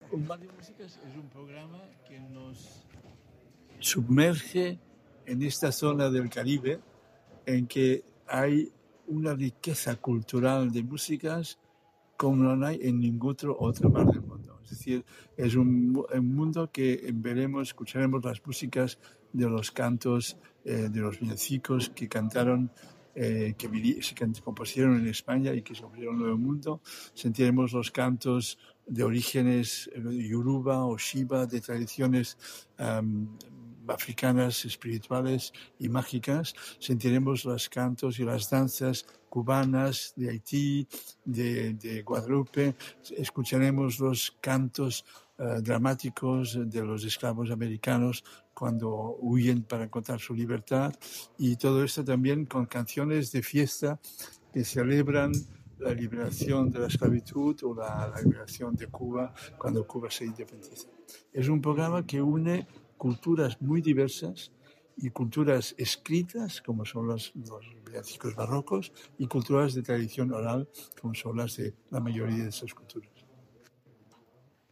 Rueda de prensa con Jordi Savall
Declaraciones de Jordi Savall
Audio declas Jordi Savall.mp3